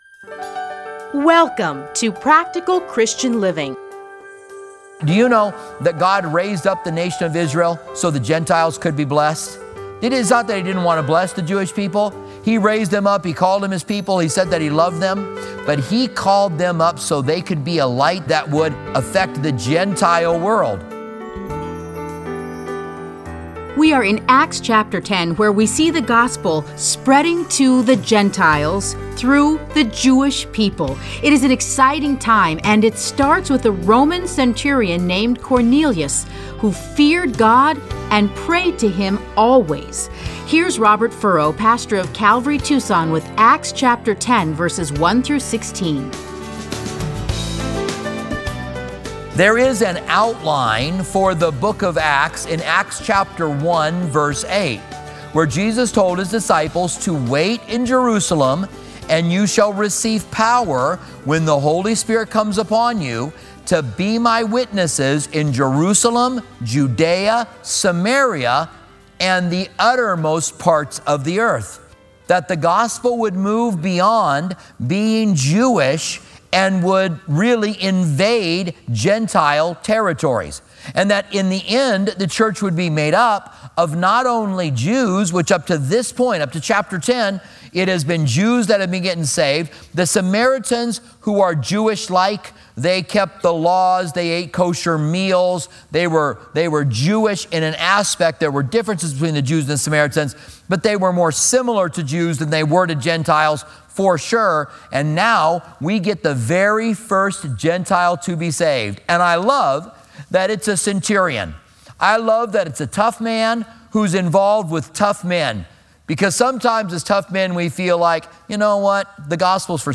Listen to a teaching from Acts 10:1-16.